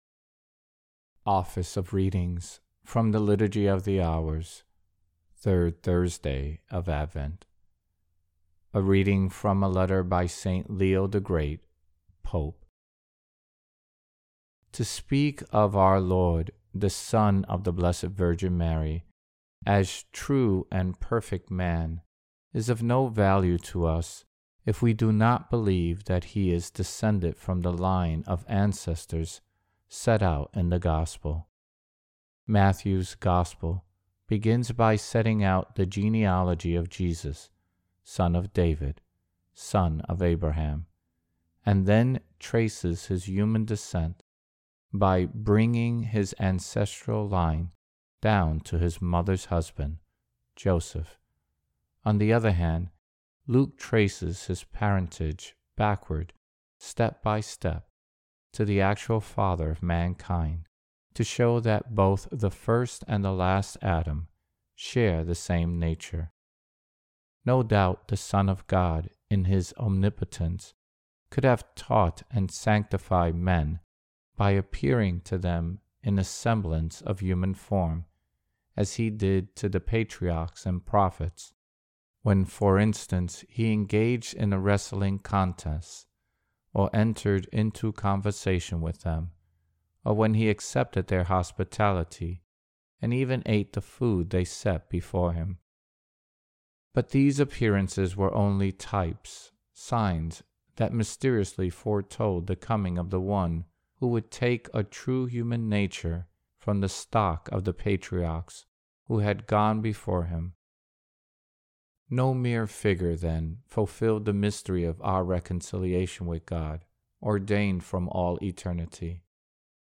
Office-of-Readings-3rd-Thursday-of-Advent.mp3